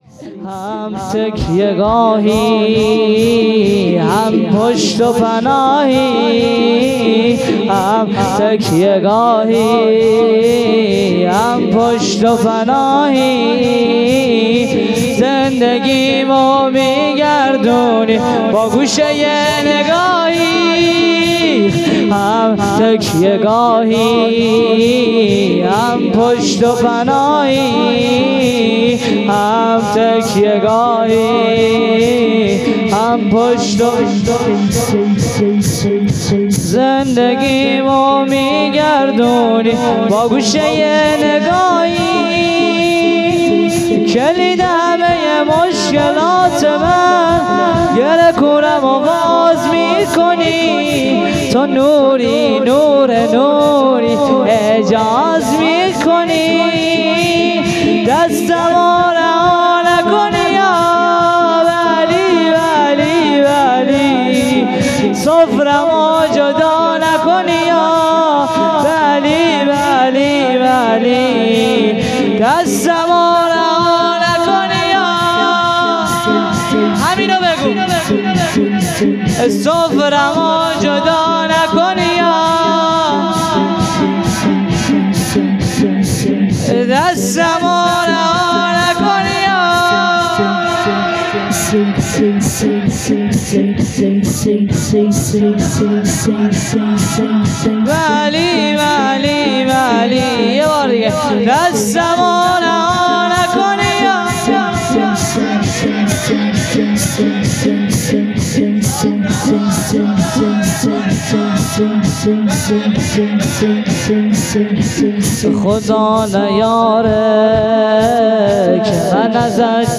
بخش سوم _ شور | هم تکیه گاهی